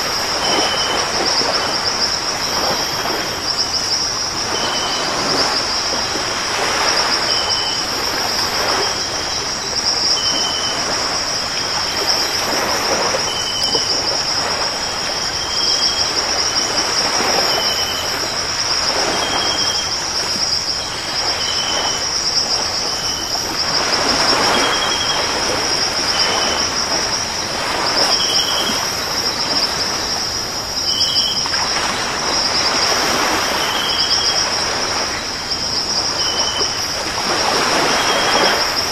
ambience_morning.ogg